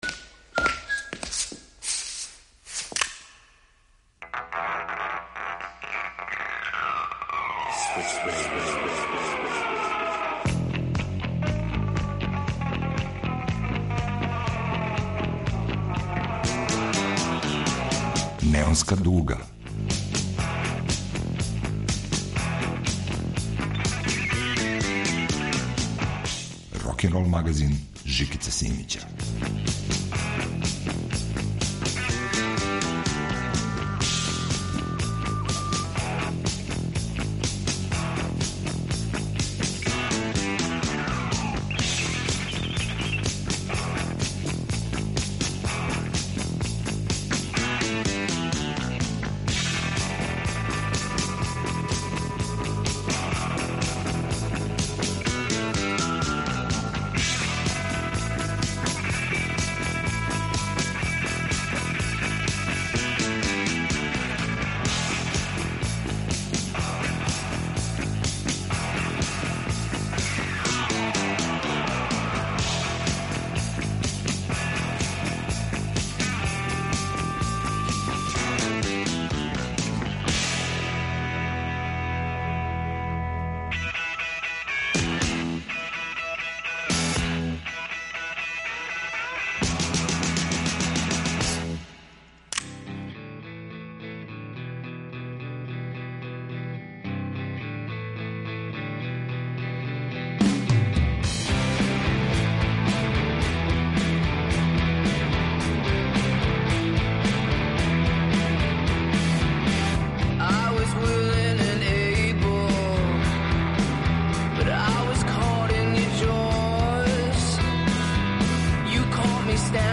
Рокенрол као музички скор за живот на дивљој страни.